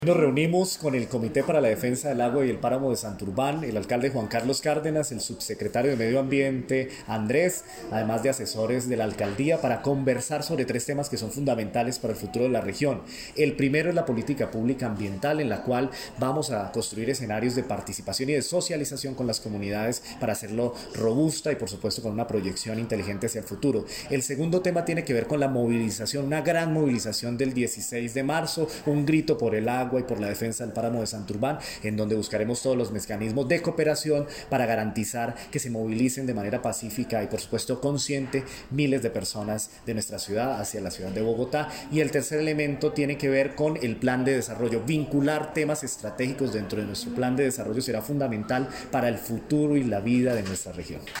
Audio: Carlos Sotomonte, alto consejero para la defensa del Páramo de Santurbán